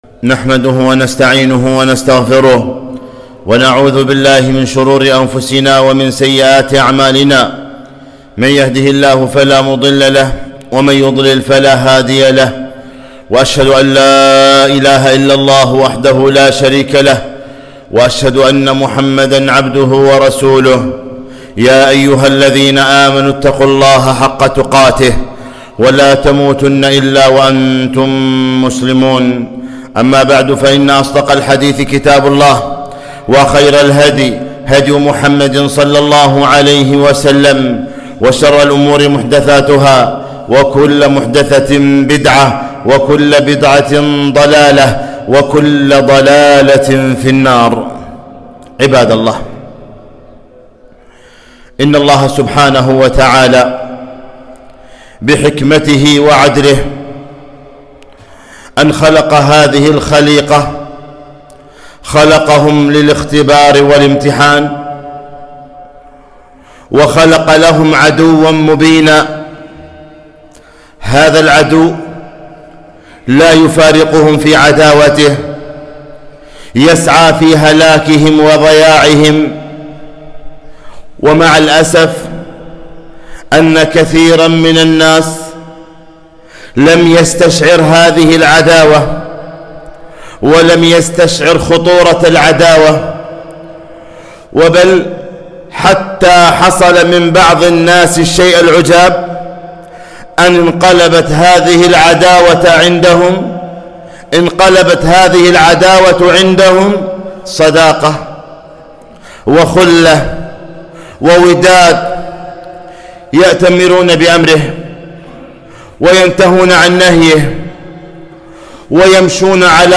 خطبة - عداوة الشيطان ج1